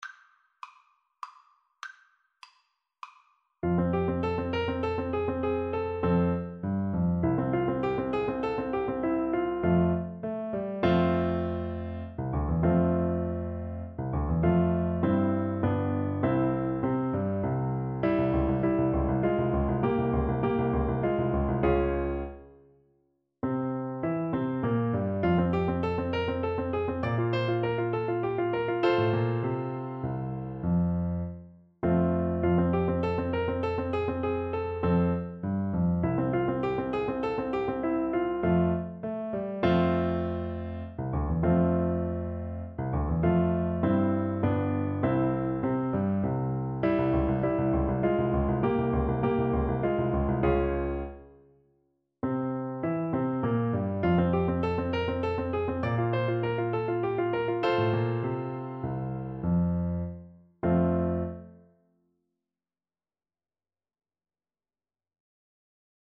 • Unlimited playalong tracks
3/4 (View more 3/4 Music)
Classical (View more Classical Bassoon Music)